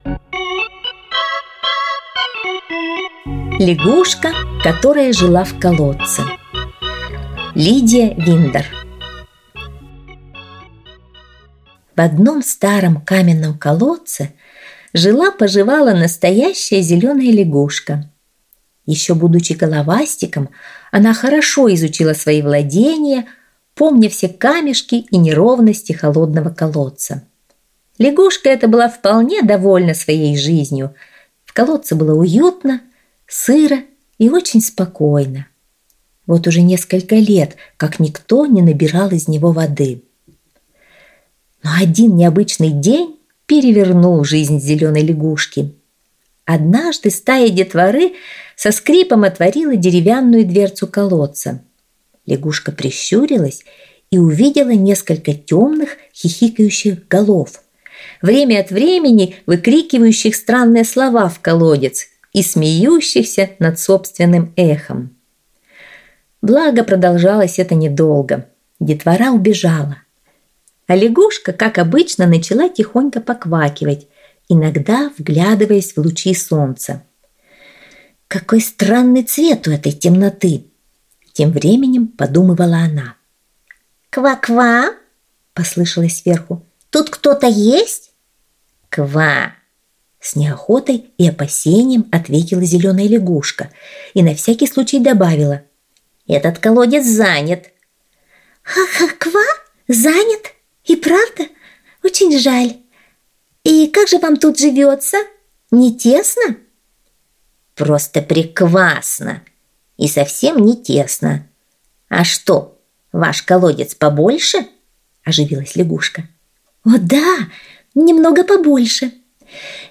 Аудиосказка «Лягушка, которая жила в колодце»